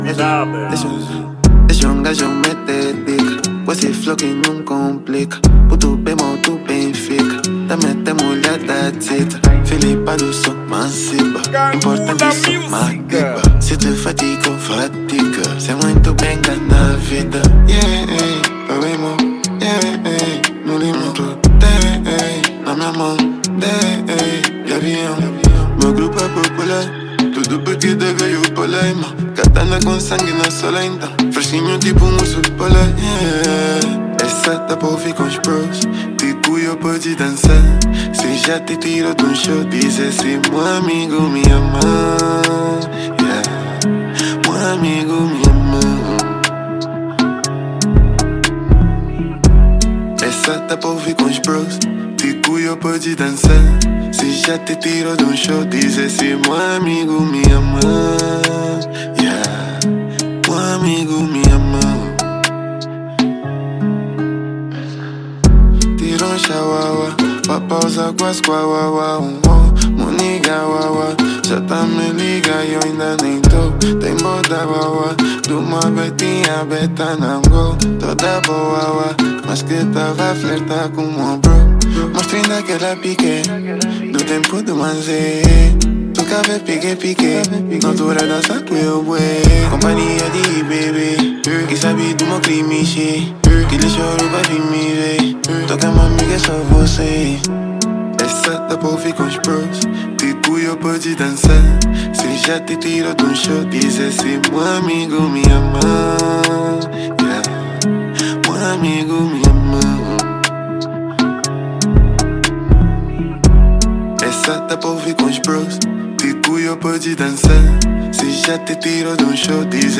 | Rap